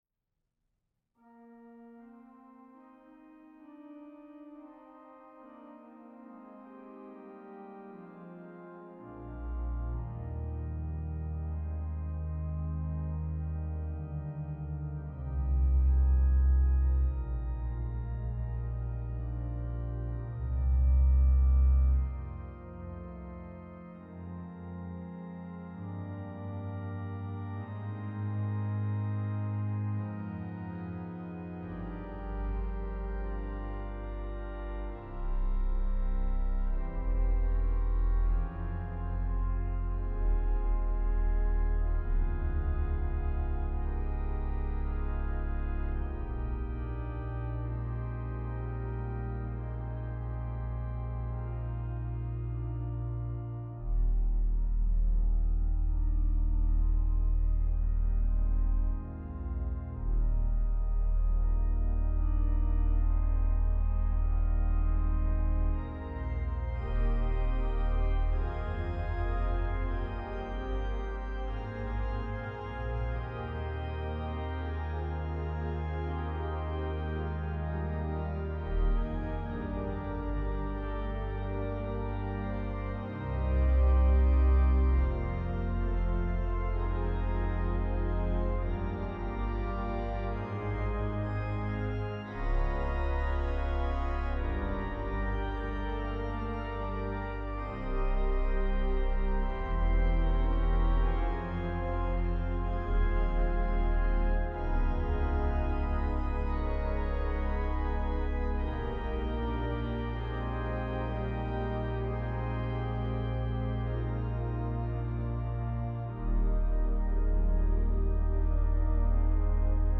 Voicing: Org 2-staff